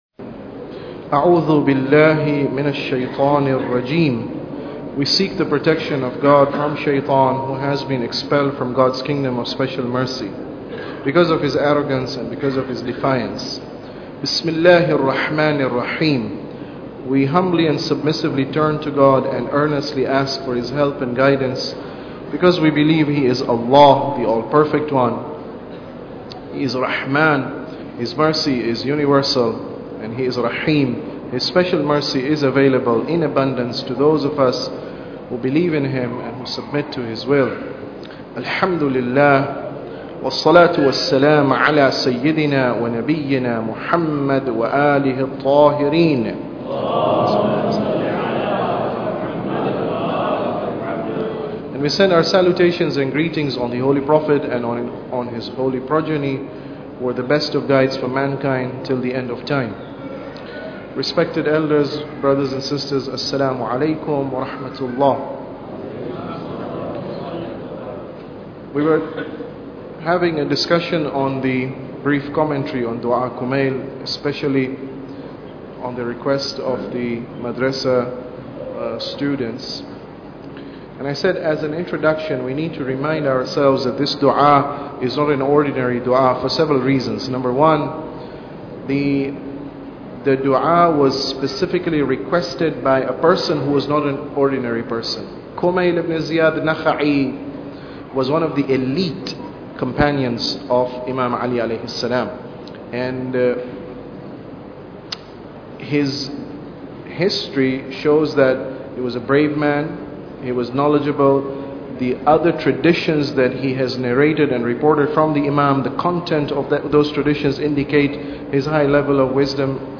Tafsir Dua Kumail Lecture 4